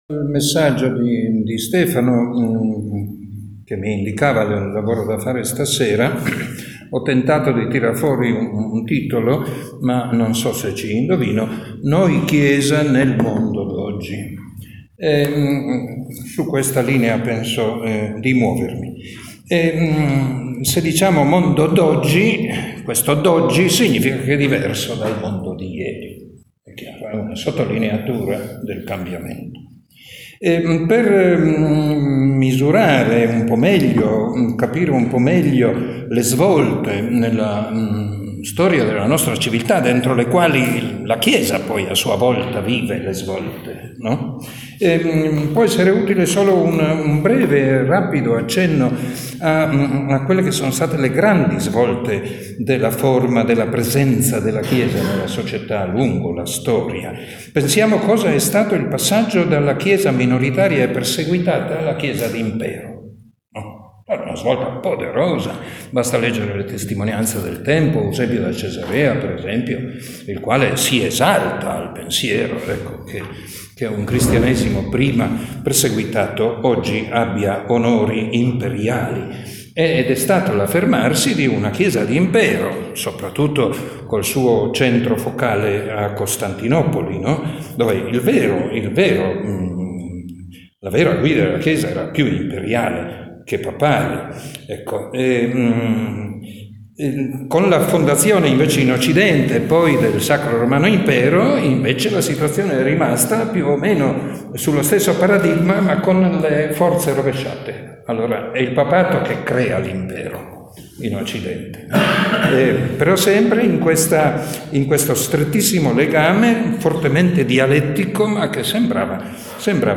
relazione
che ha introdotto l’incontro del 30 gennaio in Arcivescovado.